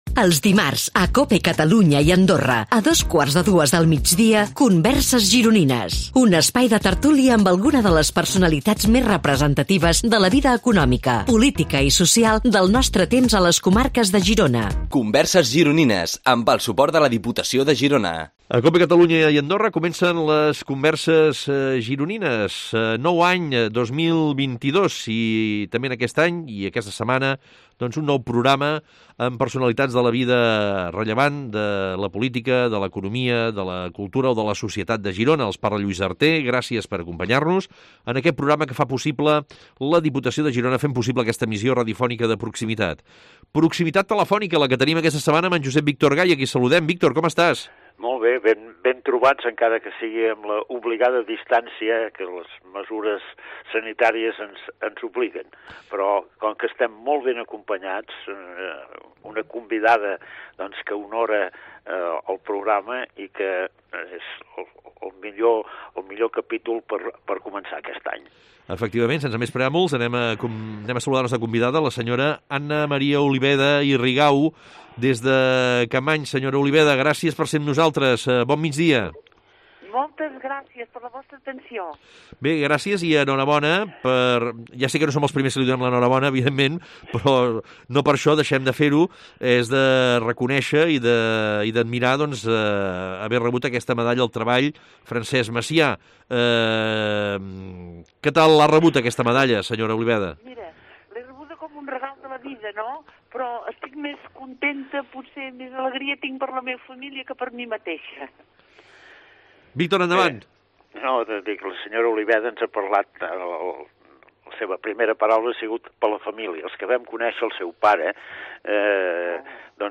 A “Converses Gironines” entrevistem algunes de les grans personalitats rellevants de la vida política, econòmica cultural o social de Girona.
Aquestes converses es creen en un format de tertúlia en el que en un clima distès i relaxat els convidats ens sorprenen pels seus coneixements i pel relat de les seves trajectòries.